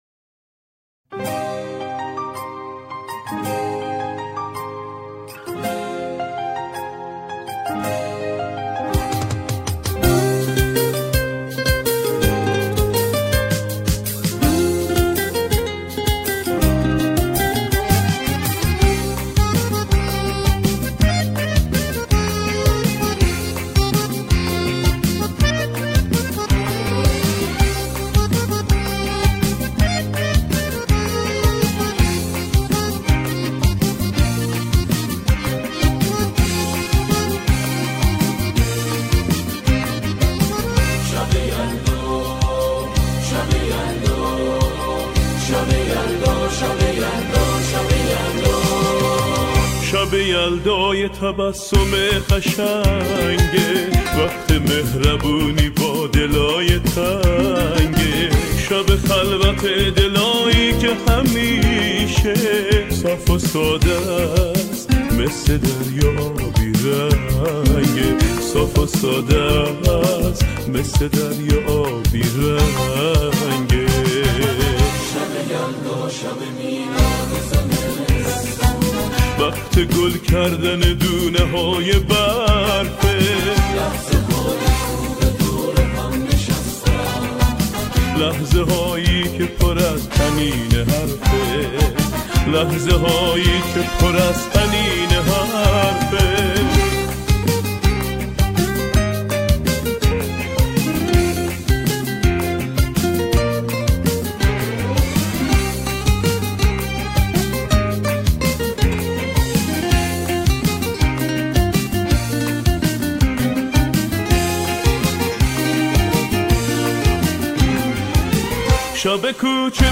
با همراهی گروه کر اجرا می‌کند.